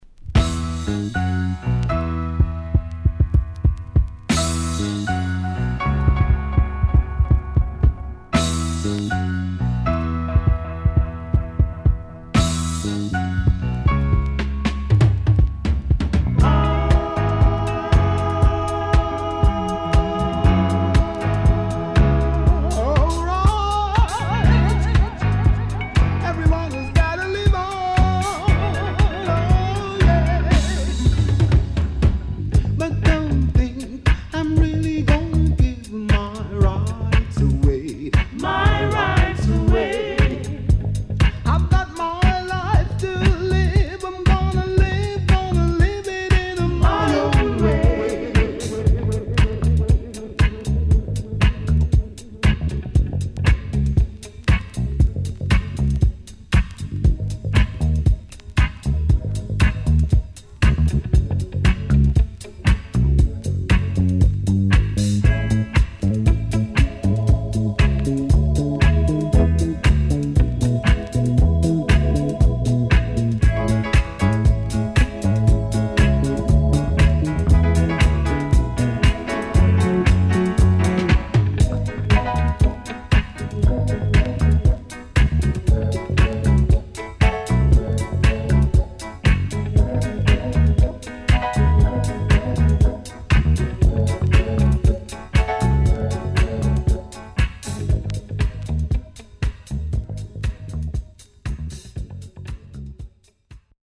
唯一無二の歌声。
SIDE A:盤質は良好です。